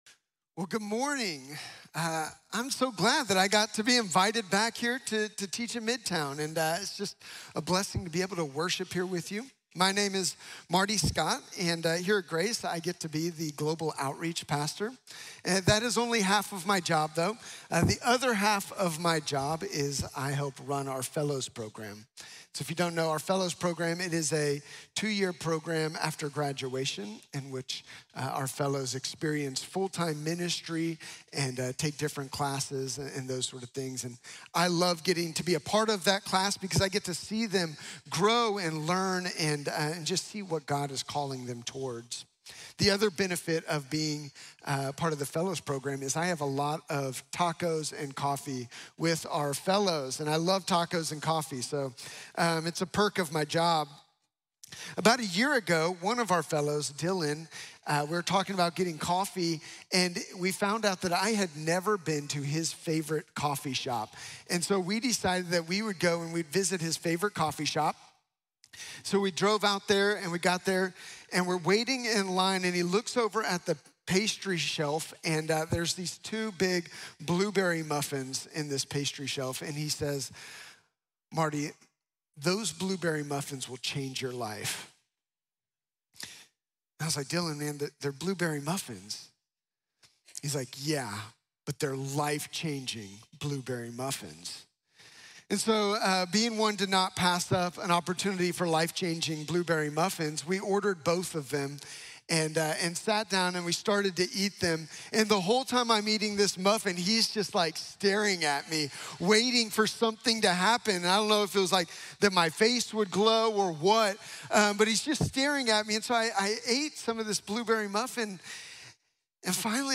I Am the Door | Sermon | Grace Bible Church